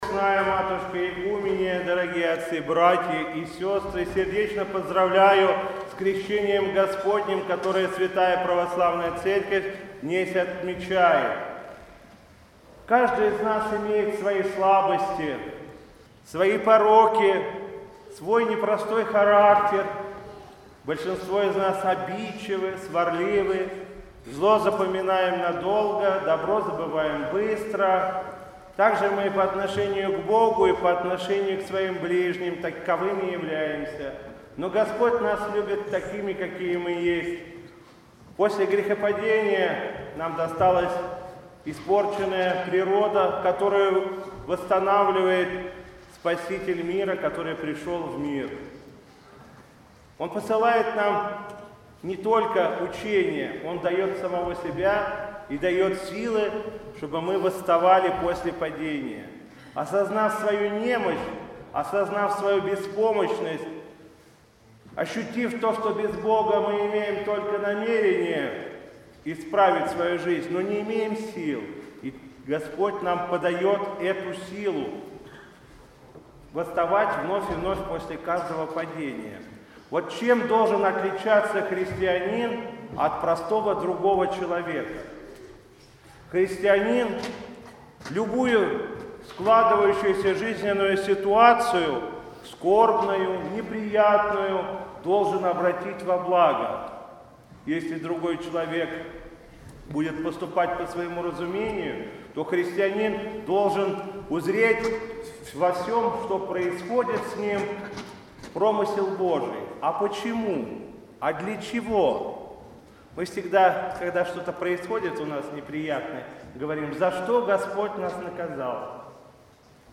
По окончании богослужения митрополит Игнатий в центре храма совершил чин великого освящения воды.
Затем владыка обратился к верующим со словами проповеди.